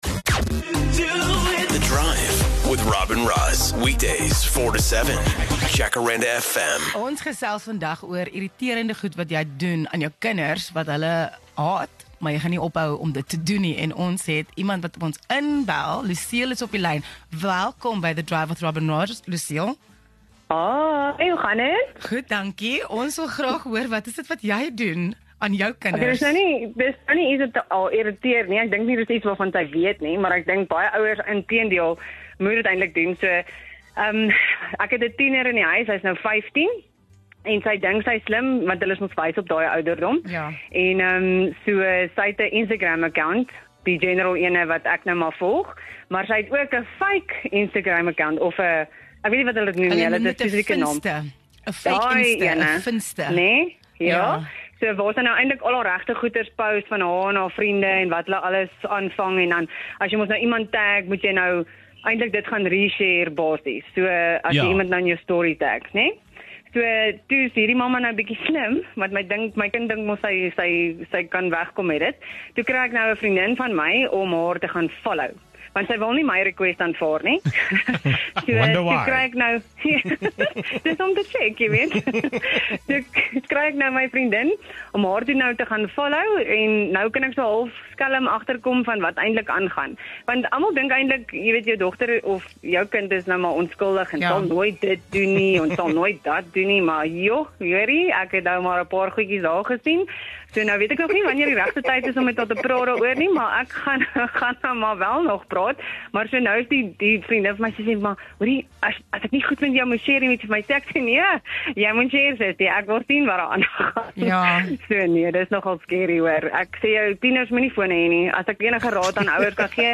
All parents have a set of behaviours that work for them, but not all of these fly with the kids, so a listener phones in and explains what she does that annoys her teenager.